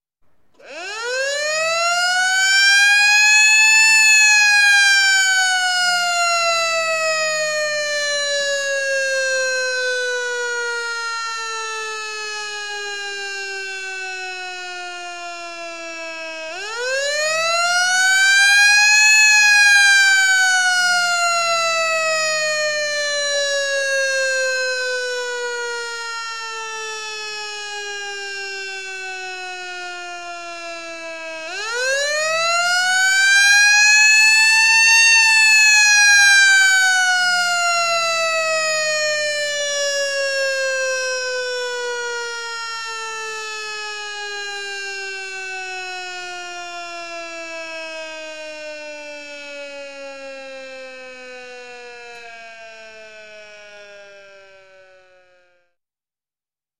На этой странице собраны звуки утреннего подъёма в армии — команда «Рота, подъём!» и сопутствующие шумы казарменной жизни.
Звук сирены при подъеме роты